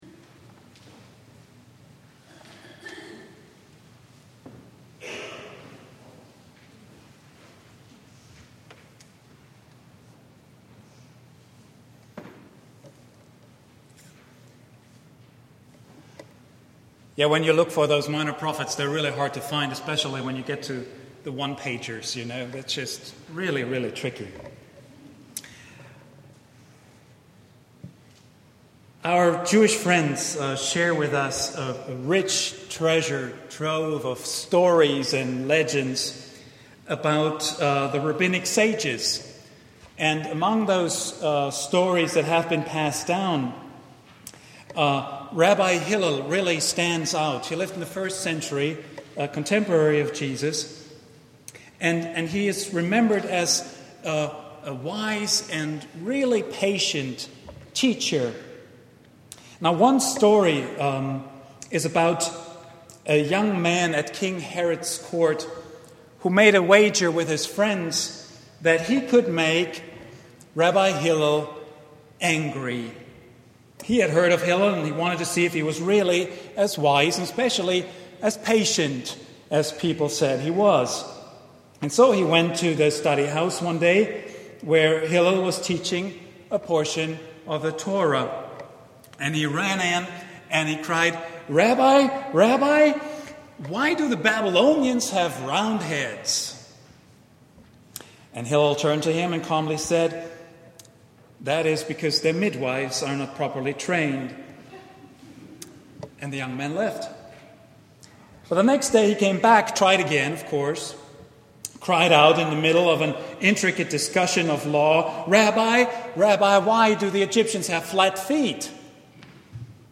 The Circle of Compassion — Vine Street Christian Church